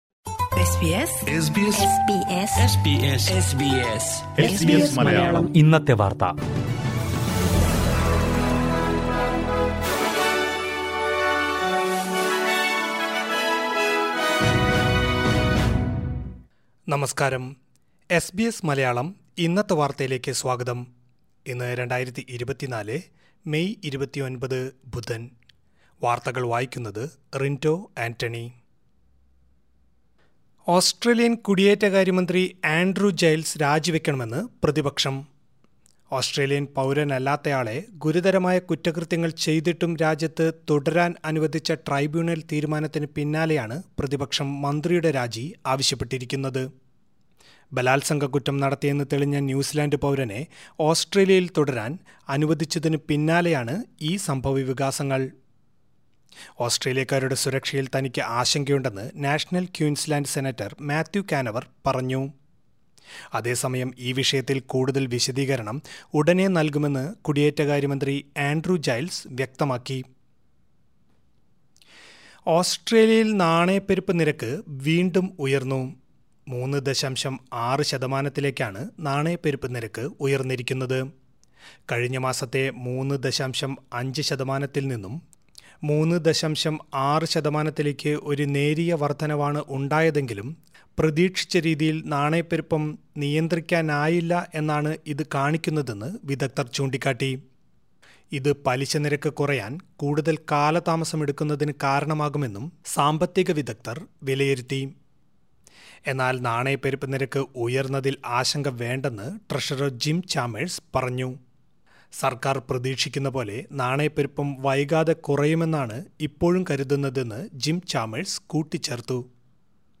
2024 മെയ് 29ലെ ഓസ്‌ട്രേലിയയിലെ ഏറ്റവും പ്രധാന വാര്‍ത്തകള്‍ കേള്‍ക്കാം...